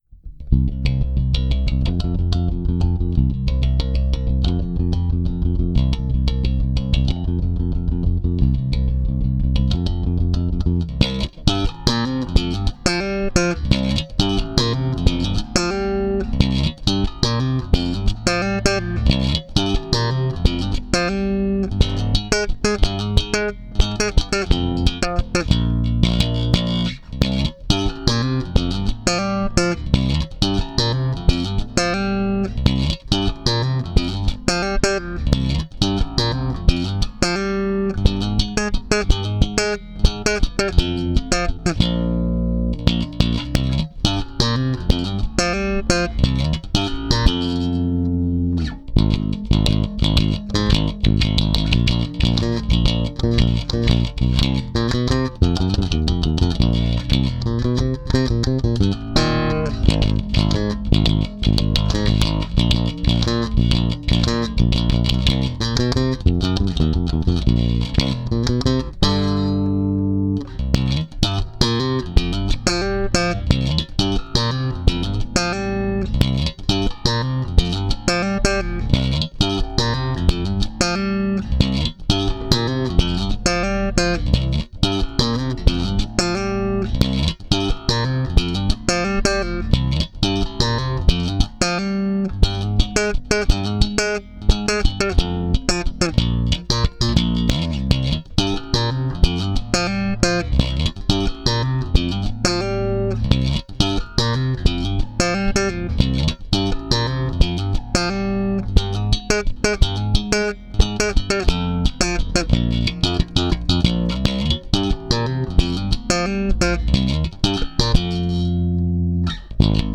bass only